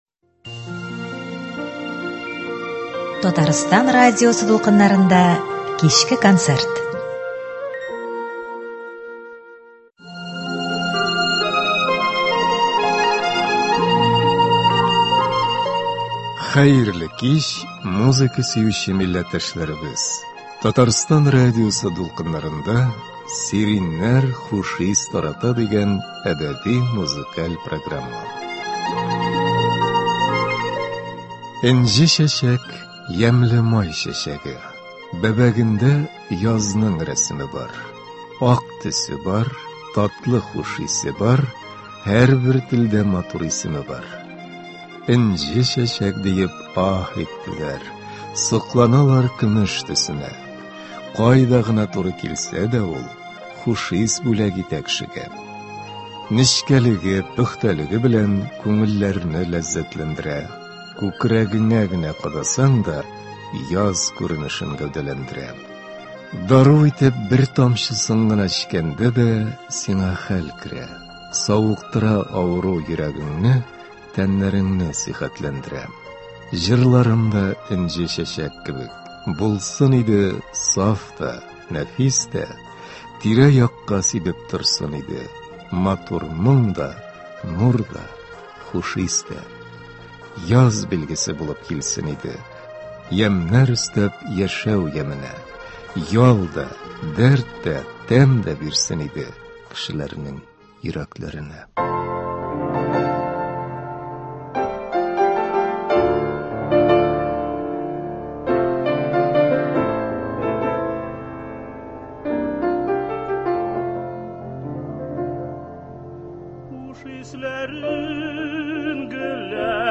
Әдәби-музыкаль программа.